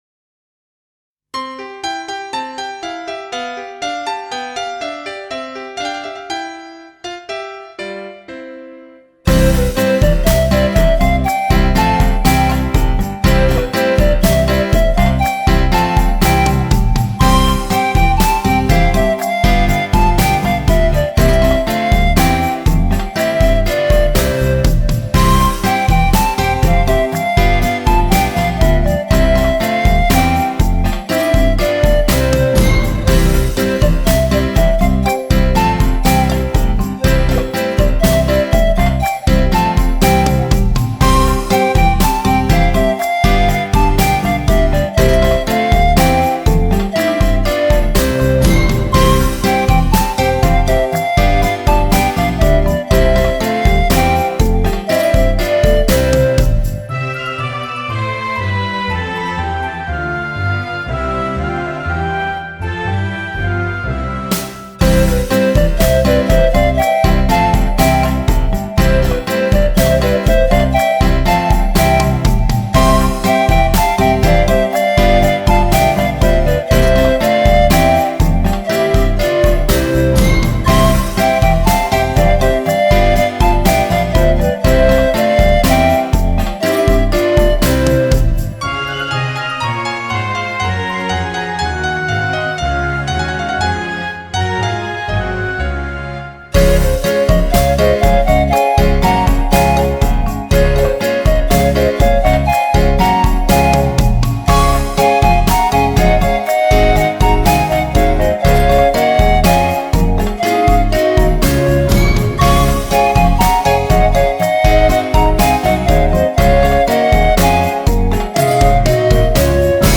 podklad-muzyczny-do-koledy.mp3